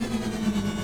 Speed_loop_6.ogg